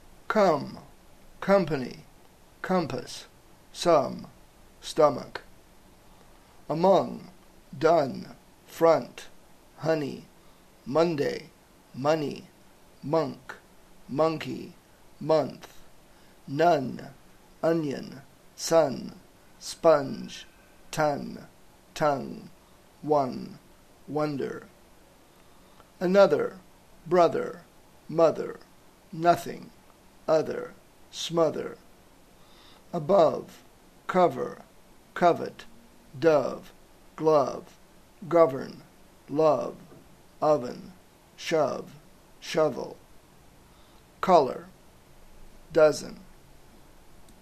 1) La O sona /ʌ/ en un gran nombre de mots, sobretot quan va davant de les consonants m, n i v i el grup th.
La O sona /ʌ/